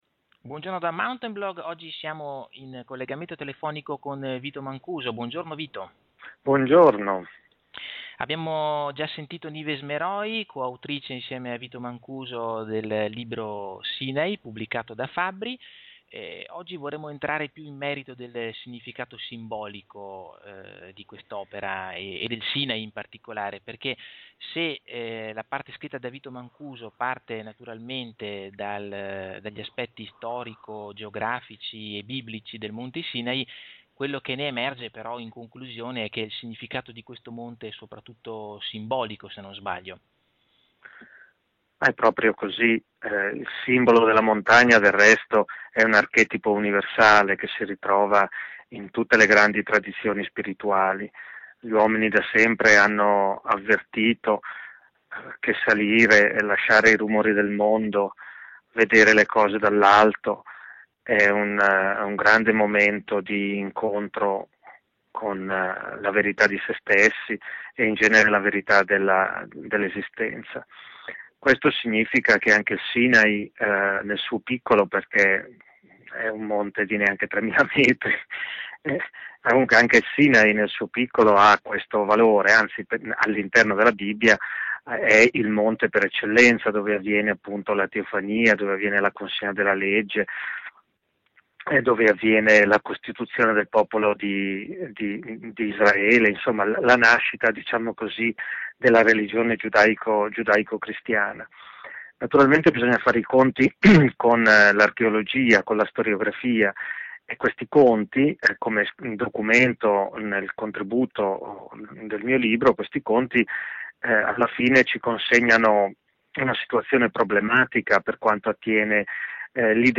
VITO MANCUSO. SINAI. Audio intervista